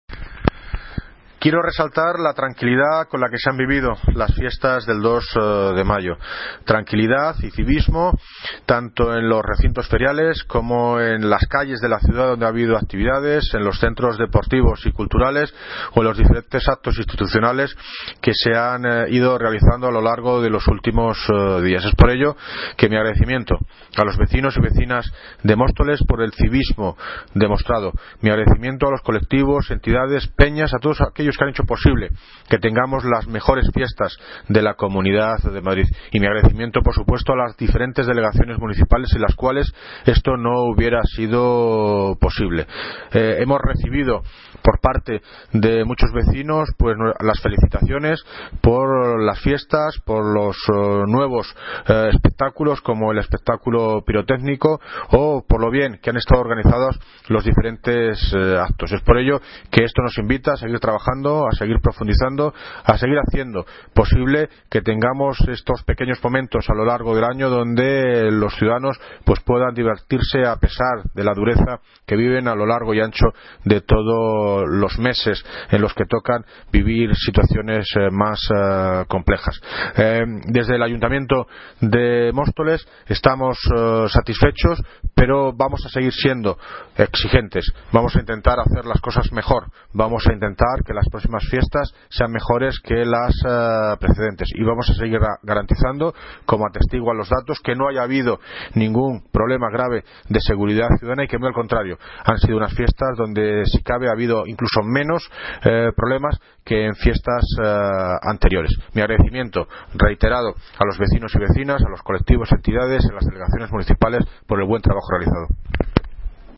Audio - David Lucas (Alcalde de Móstoles) Balance Fiestas 2 de Mayo